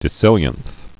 (dĭ-sĭlyənth)